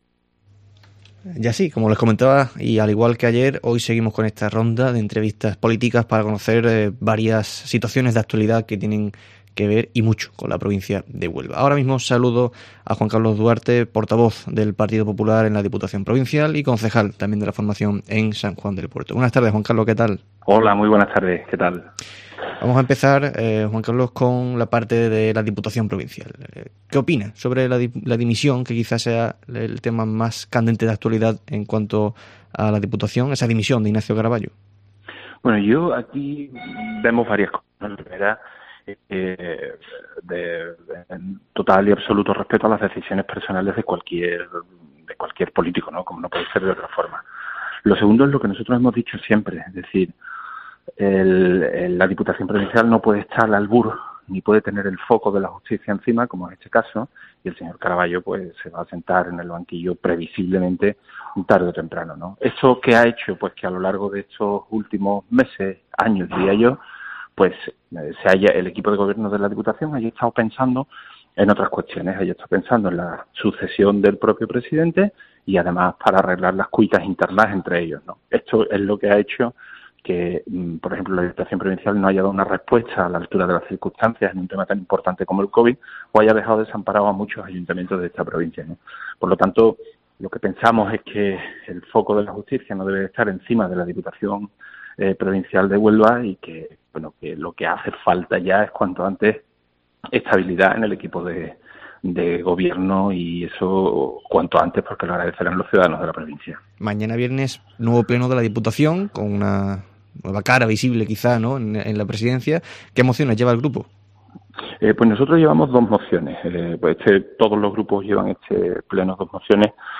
Continuamos con las entrevistas políticas en el Herrera en COPE y este jueves 5 de noviembre nos ha atendido Juan Carlos Duarte, portavoz del PP en la Diputación de Huelva.